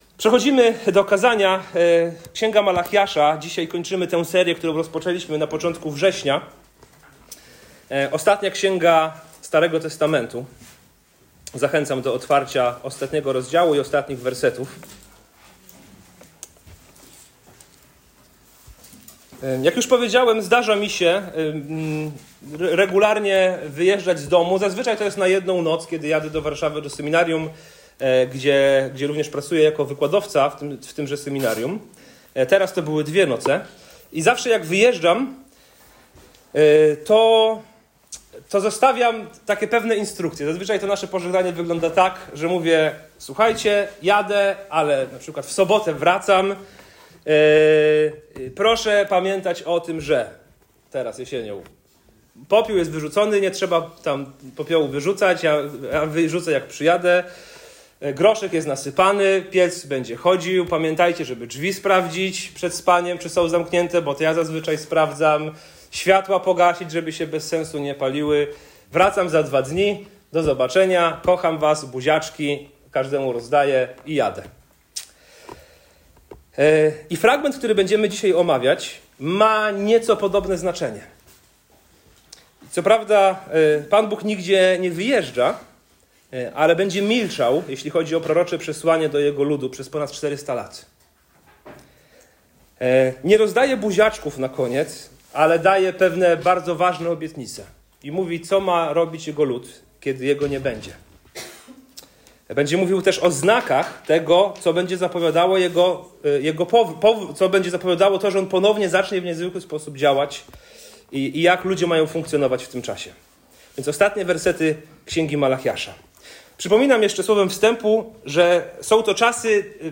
Co robić, gdy wydaje się, że Bóg milczy, a świat wokół pogrąża się w obojętności? W ostatnich słowach Starego Testamentu Bóg zostawia swojemu ludowi nie klątwę, ale obietnicę – zapowiedź nadejścia Dnia Pana i wschodu Słońca Sprawiedliwości. To kazanie przypomina, że nawet w czasach duchowej ciszy wierność, czujność i posłuszeństwo są naszym sposobem oczekiwania na Boga, który na pewno powróci.